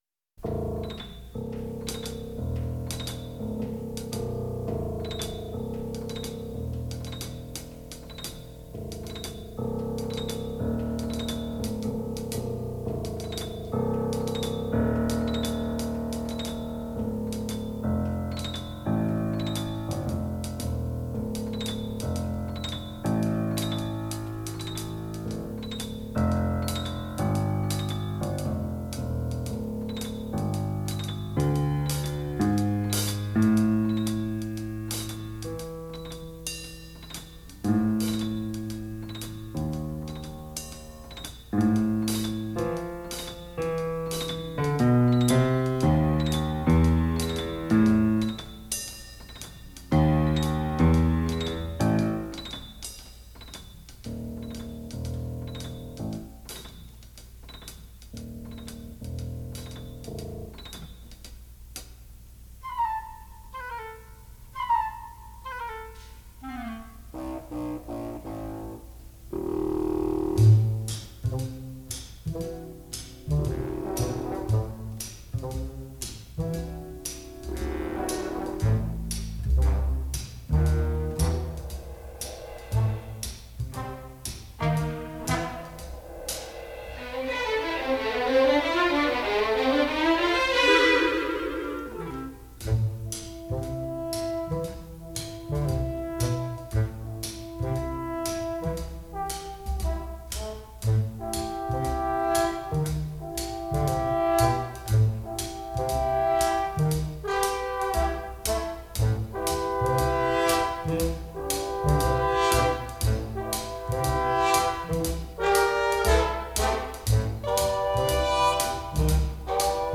• MAINSTREAM (JAZZ)
• SYMPHONIC (JAZZ)
• Piano
• Violin
• Trumpet
• Alto Sax
• Trombone
• Bass
• Drums